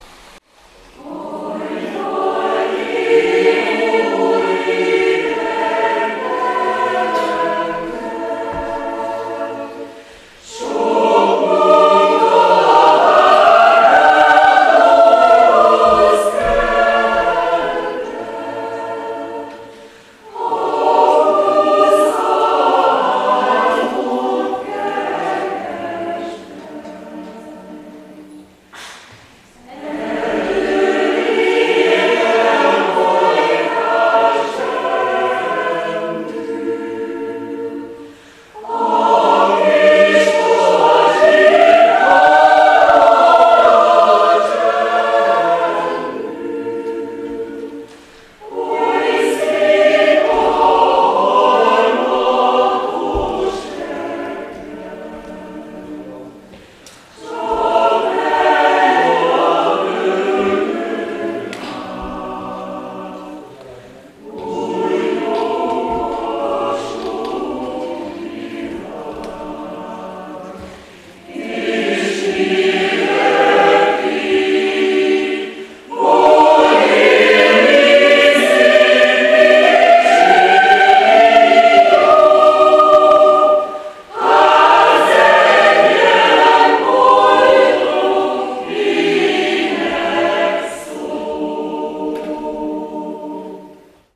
Aufnahmen vom Konzert in Fülek (SK), 04.06.2009